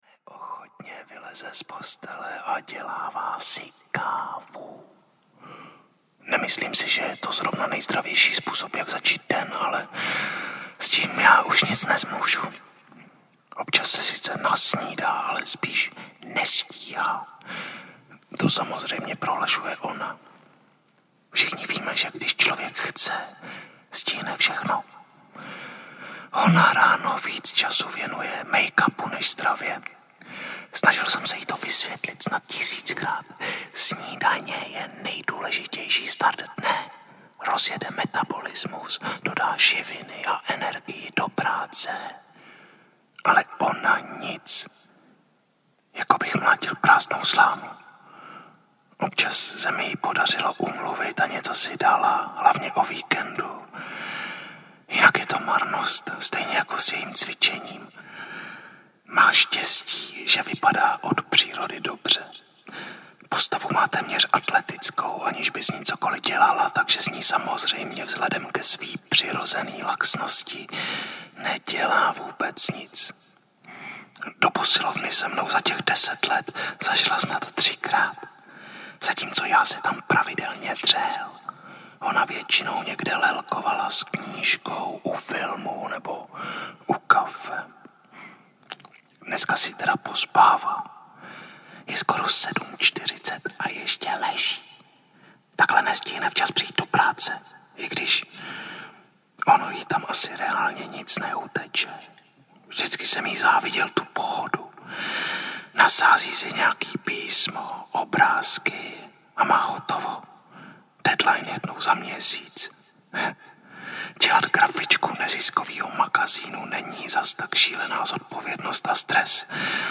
Našeptávači audiokniha
Ukázka z knihy
Audiokniha Našeptávači spojuje literární vyprávění s fenoménem ASMR. Devět autorských povídek je čteno šeptem nebo tlumeným hlasem – vždy tak, aby forma odpovídala samotnému příběhu.